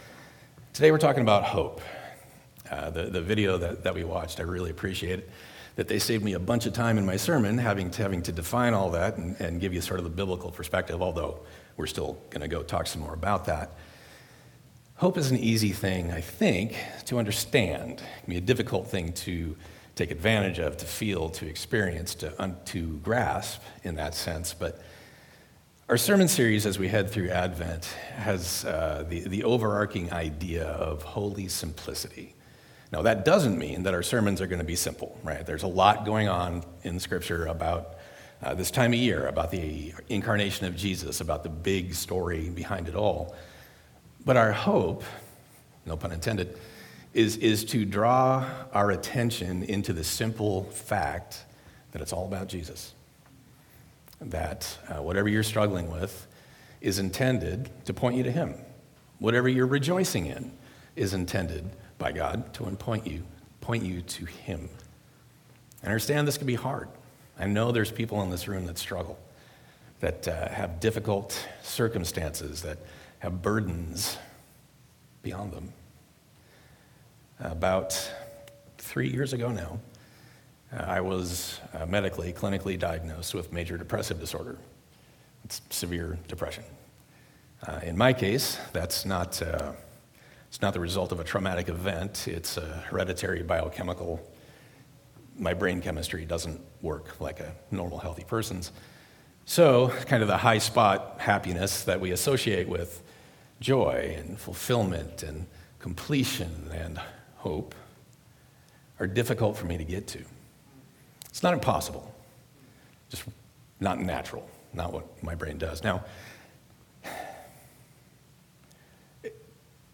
sermon_12_1_24.mp3